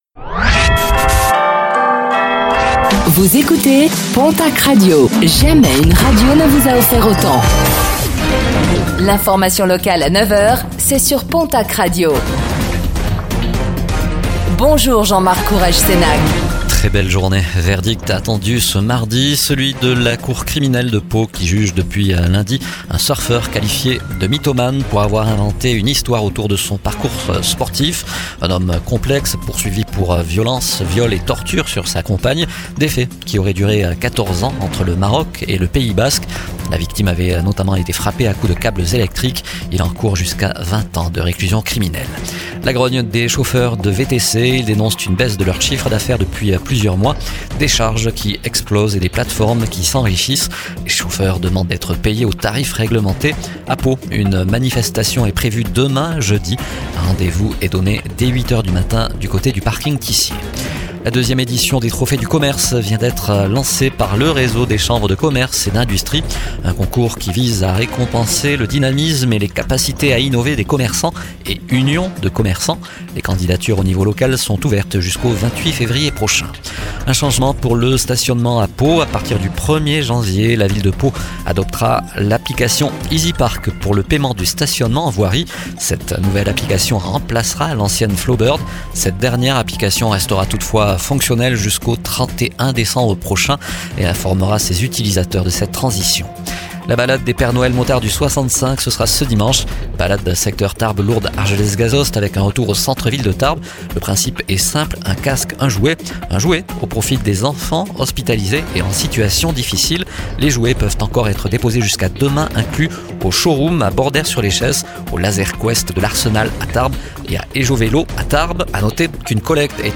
09:05 Écouter le podcast Télécharger le podcast Réécoutez le flash d'information locale de ce mercredi 17 décembre 2025